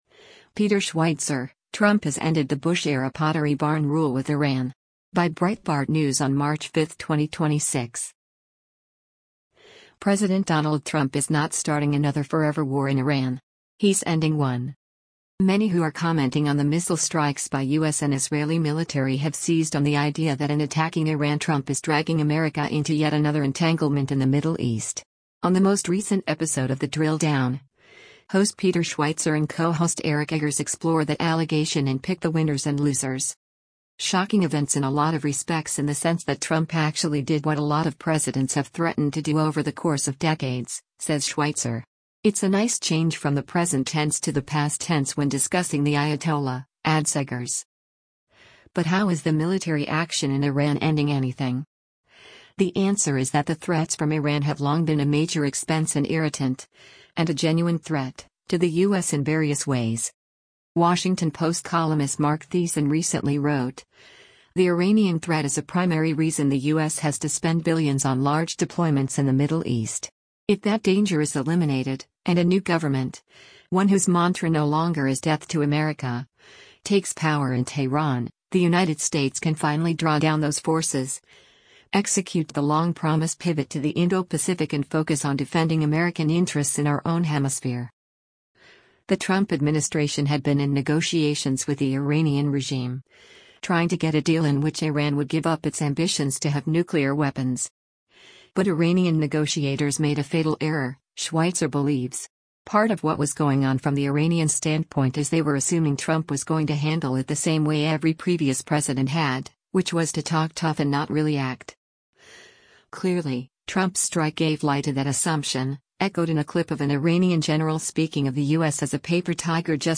Clearly, Trump’s strike gave lie to that assumption, echoed in a clip of an Iranian general speaking of the U.S. as a “paper tiger” just before the bombs started falling.